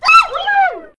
dog sounds